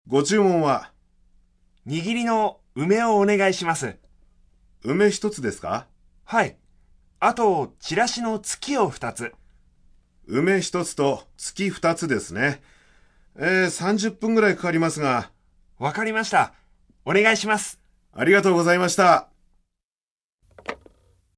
電話（自宅）
会話例●話す ●聞く ○読む ○書く 　依頼、指示、命令する